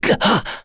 Kain-Vox_Damage_kr_04.wav